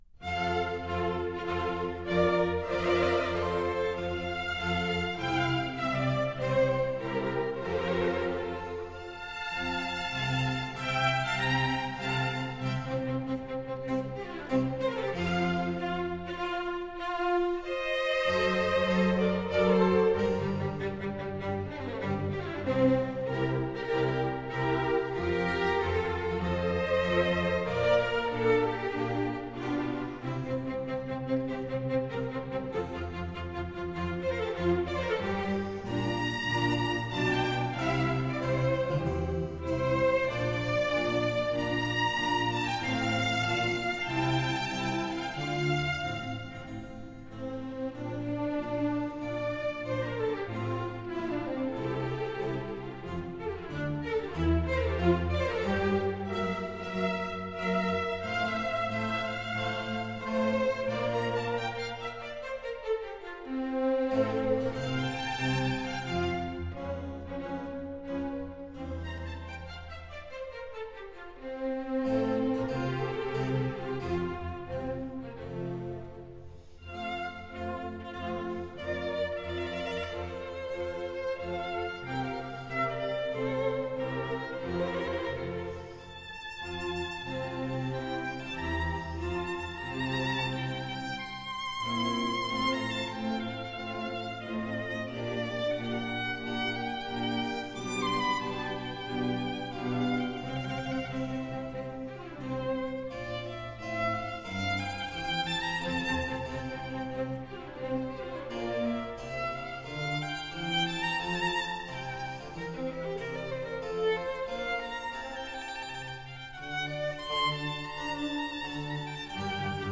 圣马丁乐队的泰勒曼小提琴协奏曲（小提琴独奏、指挥是由伊奥娜.布朗担任）